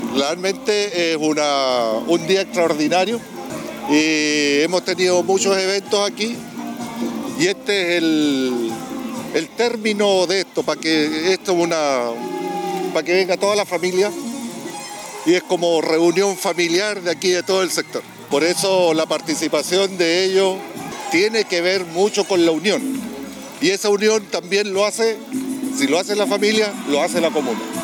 Pedro-Greve-Concejal-de-Temuco.mp3